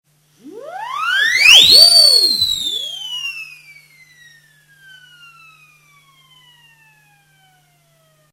ACME Whistle siren 147
Musical sound effect used by percussionists